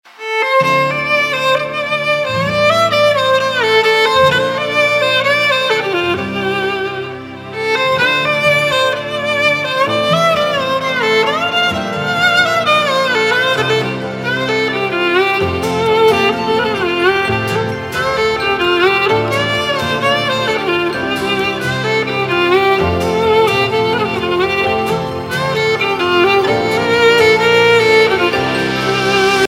Ringtones Mp3